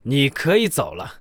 文件 文件历史 文件用途 全域文件用途 Kg_fw_01.ogg （Ogg Vorbis声音文件，长度1.2秒，120 kbps，文件大小：18 KB） 源地址:地下城与勇士游戏语音 文件历史 点击某个日期/时间查看对应时刻的文件。 日期/时间 缩略图 大小 用户 备注 当前 2018年5月13日 (日) 02:11 1.2秒 （18 KB） 地下城与勇士  （ 留言 | 贡献 ） 分类:卡坤 分类:地下城与勇士 源地址:地下城与勇士游戏语音 您不可以覆盖此文件。